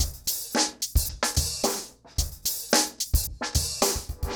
RemixedDrums_110BPM_13.wav